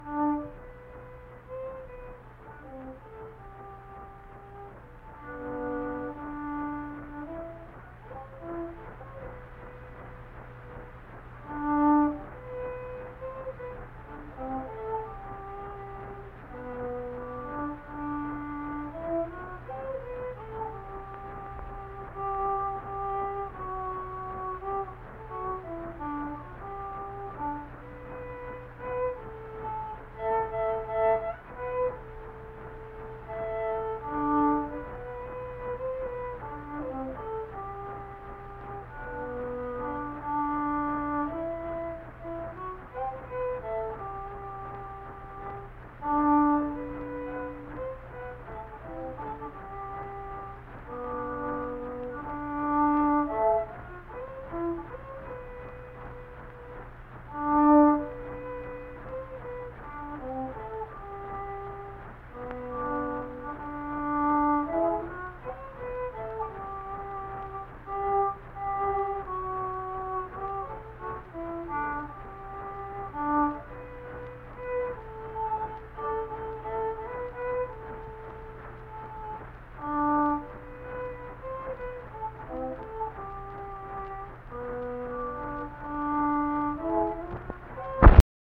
Unaccompanied fiddle music performance
Hymns and Spiritual Music, Instrumental Music
Fiddle
Harrison County (W. Va.)